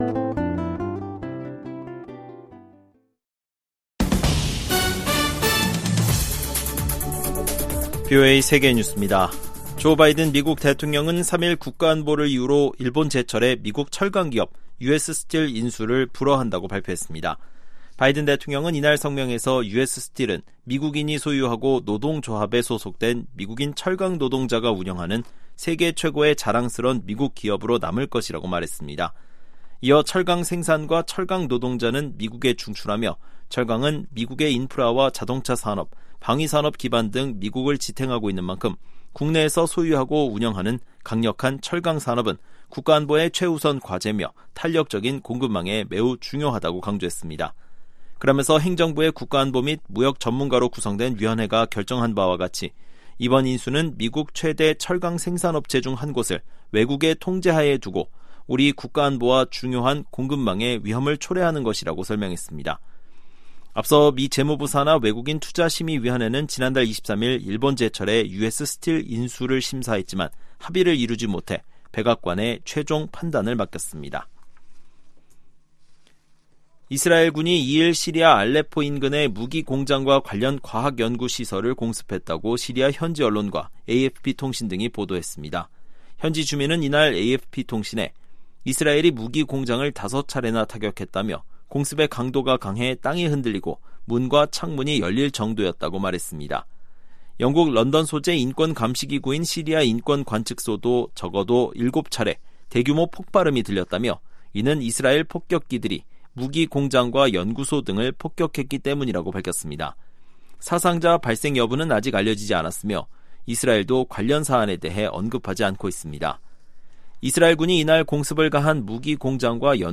VOA 한국어 아침 뉴스 프로그램 '워싱턴 뉴스 광장'입니다. 한국에서 현직 대통령에 대한 사법당국의 체포 시도라는 사상 초유의 일이 벌어졌습니다. 최상목 한국 대통령 권한대행 부총리 겸 기획재정부 장관은 오늘(3일) 오후 정부서울청사에서 필립 골드버그 주한 미국대사와 제이비어 브런슨 주한미군사령관을 공동 접견했습니다. 2025년 새해가 밝았지만, 북한군 병사들이 타국의 전쟁터에서 희생되고 있다는 소식이 이어지고 있습니다.